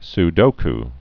(s-dōk)